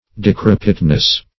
Decrepitness \De*crep"it*ness\, n.
decrepitness.mp3